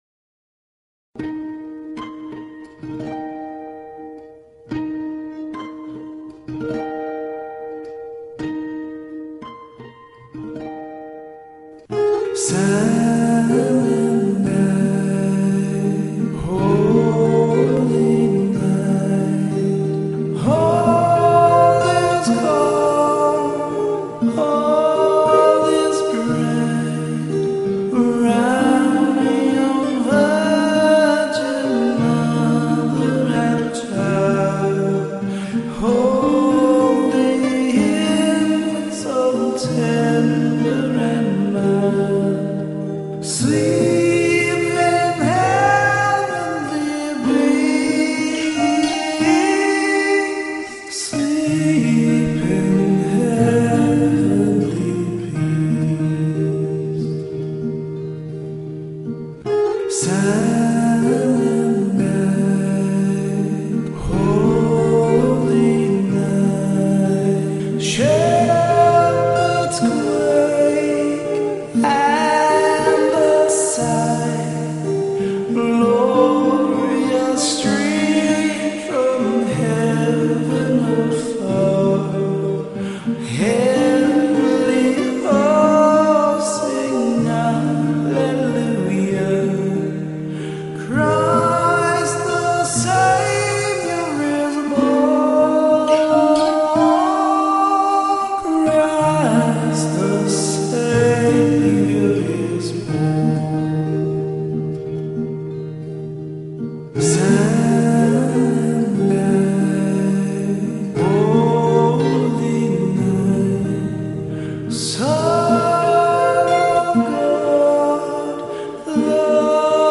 Talk Show Episode, Audio Podcast, Bridging_Wisdom and Courtesy of BBS Radio on , show guests , about , categorized as